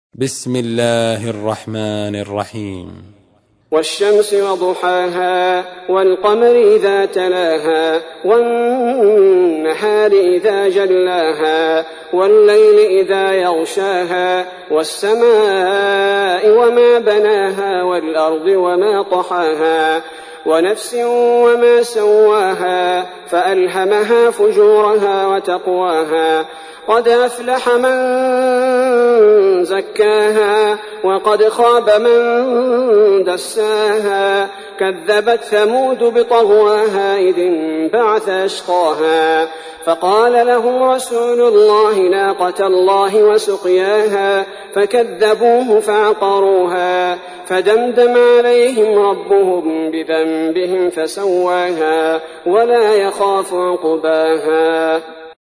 تحميل : 91. سورة الشمس / القارئ عبد البارئ الثبيتي / القرآن الكريم / موقع يا حسين